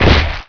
swoop1hit.wav